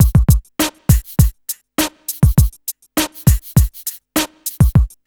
HF101BEAT4-L.wav